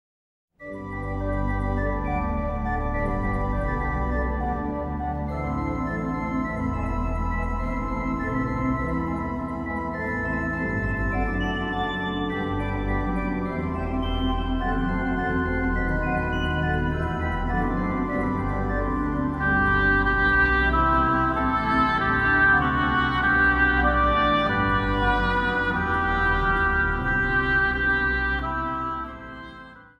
Instrumentaal | Dwarsfluit
Instrumentaal | Hobo
Instrumentaal | Klavecimbel
Instrumentaal | Orkest
Instrumentaal | Panfluit
Instrumentaal | Trompet